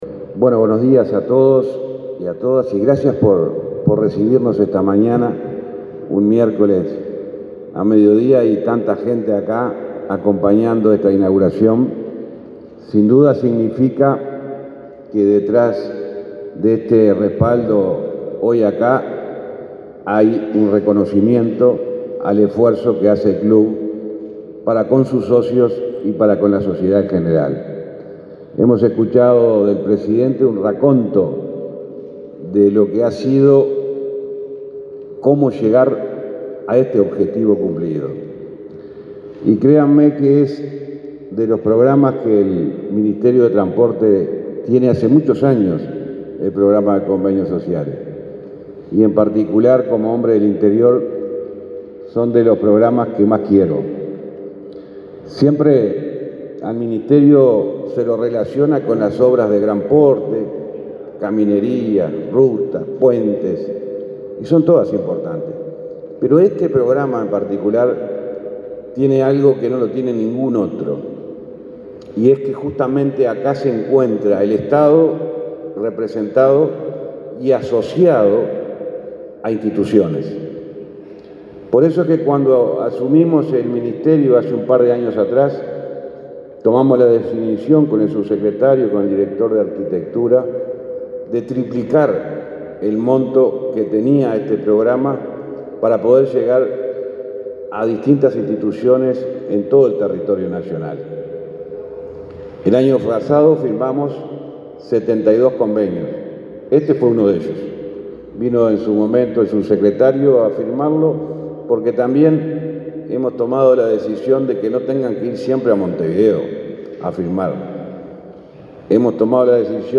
Palabras del ministro de Transporte, José Luis Falero
El titular del Ministerio de Transporte y Obras Públicas, José Luis Falero, participó, en Tacuarembó, en la inauguración de obras en el Club Atlético